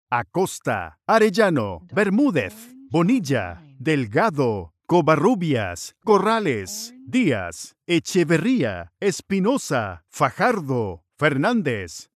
8-APELLIDOS-LATINOS-GRADUACION-SOUNDMARK.mp3